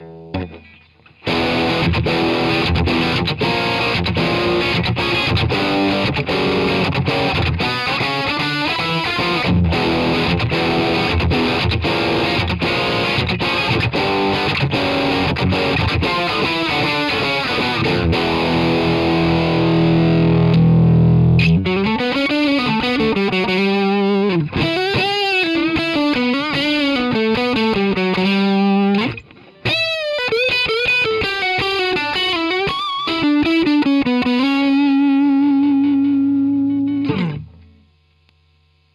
Zapol som, vybral som British Lead 1, vsetko do prava, gain na 3 a hned som mal moj kapelovy zvuk, aky mam z Valwattu.